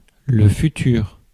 Ääntäminen
France (Paris): IPA: [lœ fy.tyʁ]